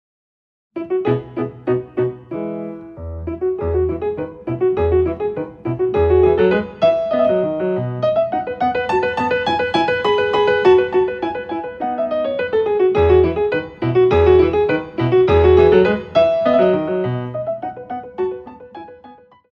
Ballet class music for advanced dancers